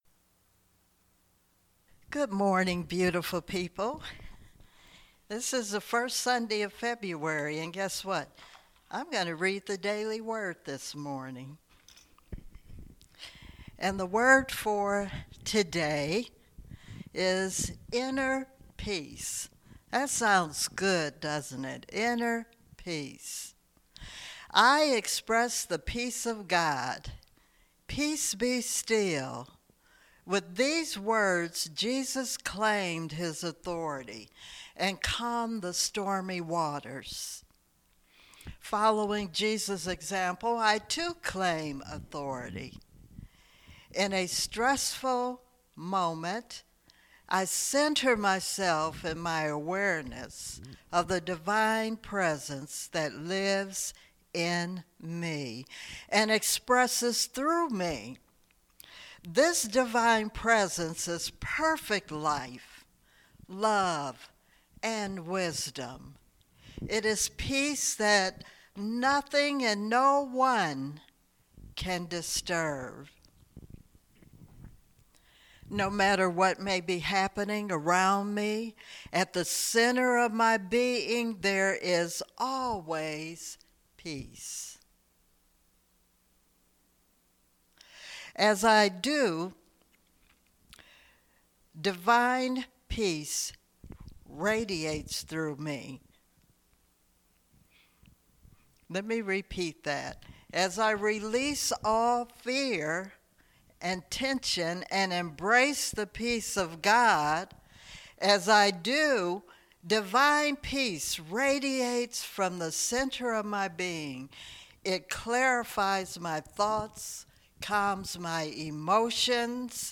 Spiritual Leader Series: Sermons 2021 Date